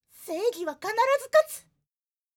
josei_seigihakanarazukatu.mp3